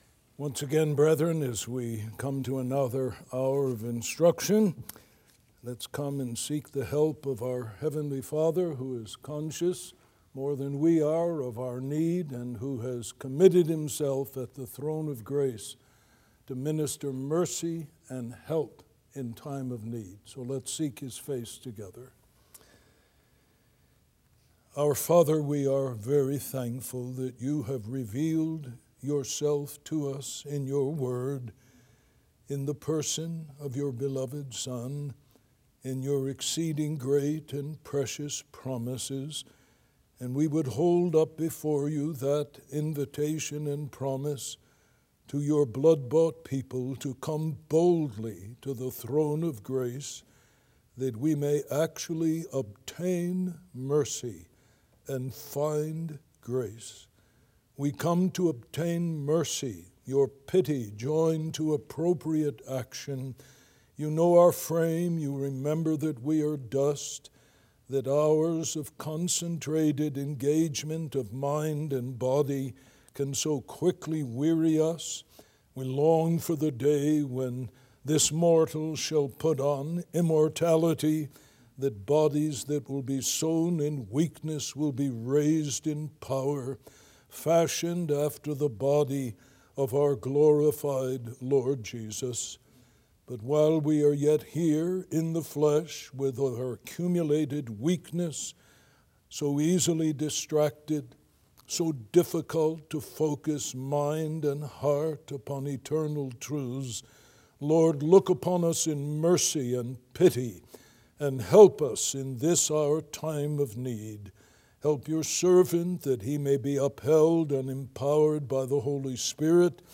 Lecture 4 – Biblical Motives for Evangelism – Being Taught that We may Teach